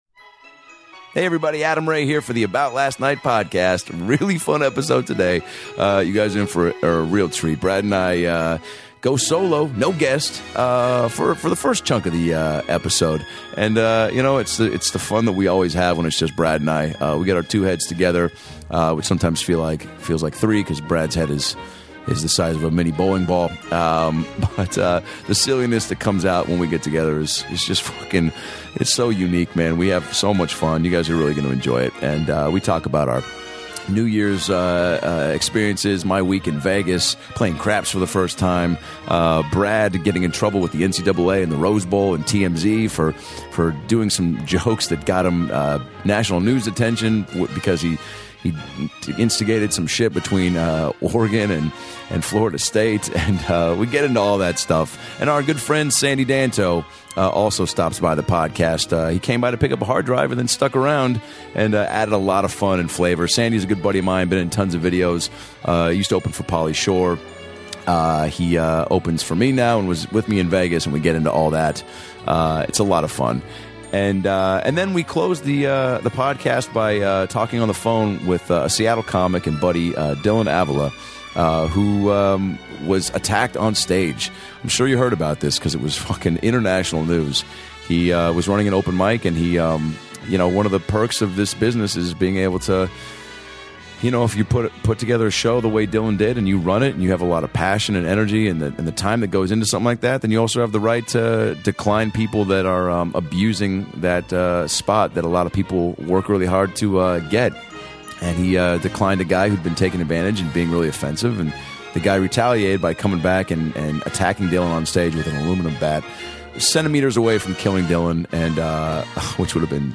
had a 15 minute phone call interview that will inspire you, and make you laugh.